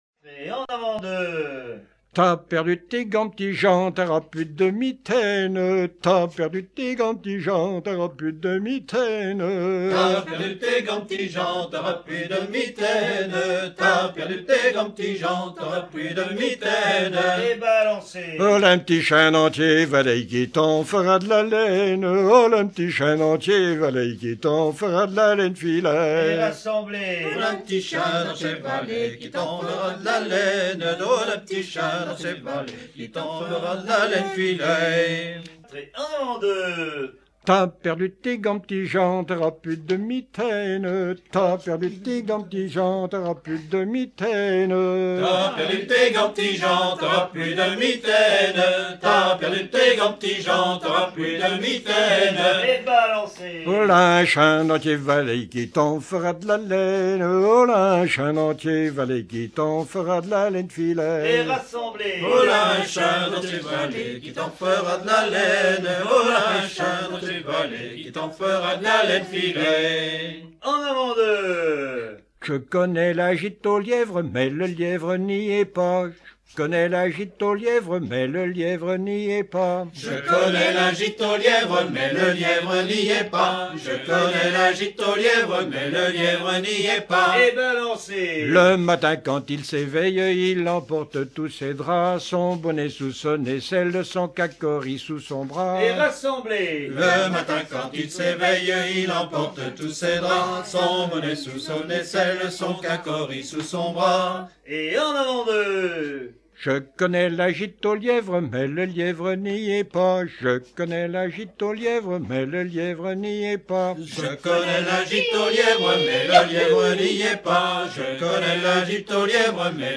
Mémoires et Patrimoines vivants - RaddO est une base de données d'archives iconographiques et sonores.
Pièce musicale éditée